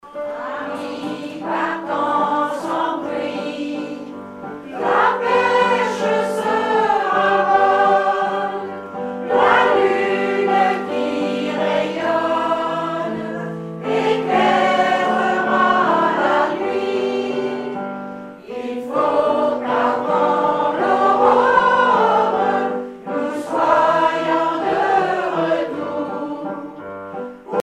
Informateur(s) Chorale de Miquelon association
Genre strophique
repiquage d'une cassette audio
Pièce musicale inédite